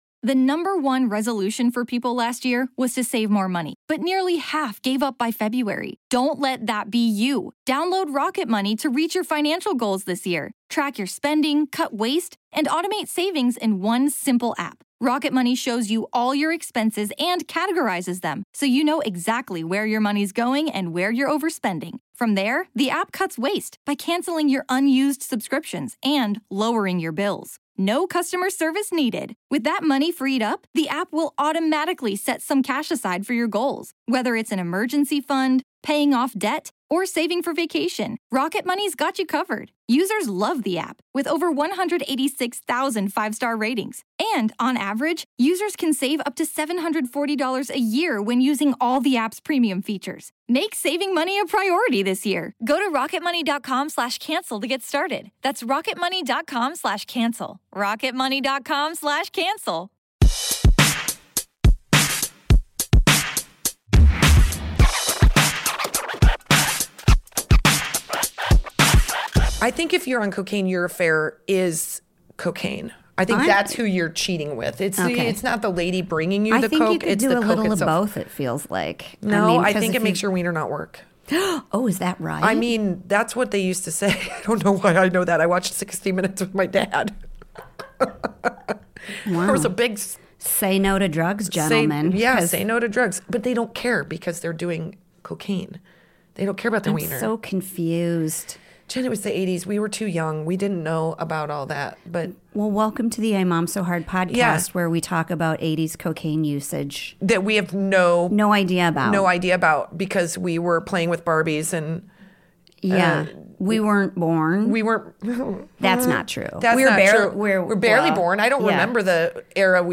Hear all about it on today’s episode wherever you get your podcasts -- ABOUT US: This hilarious comedy podcast about motherhood is for moms by moms talking all about being a mom.
Female comedy duo